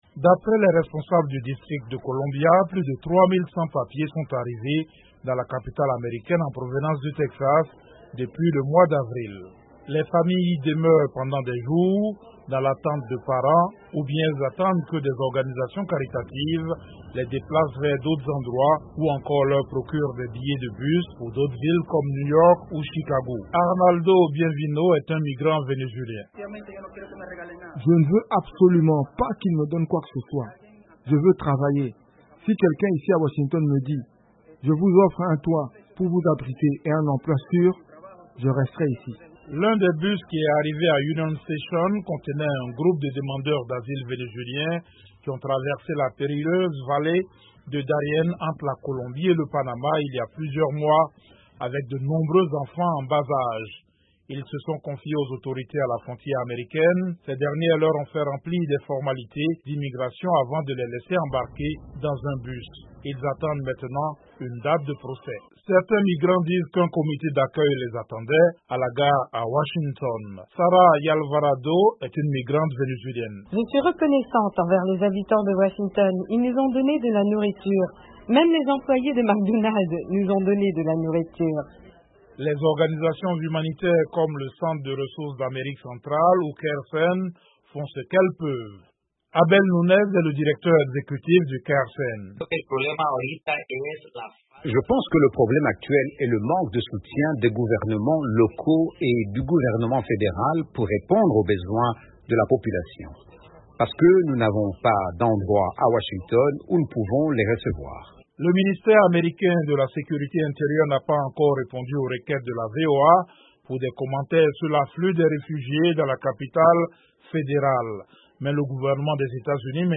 Des bus remplis de migrants continuent d'arriver à Washington en provenance du Texas. Ils sont envoyés par Greg Abbott, le gouverneur républicain de l'Etat, qui estime que le président démocrate Joe Biden n'a pas fait assez pour sécuriser la frontière. Reportage